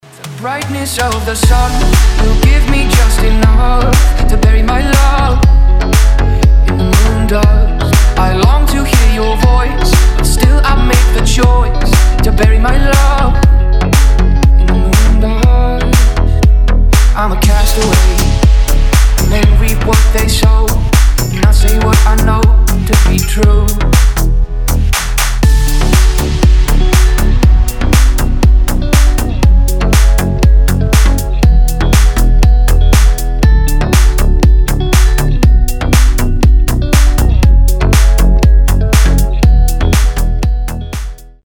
deep house
мелодичные
красивая мелодия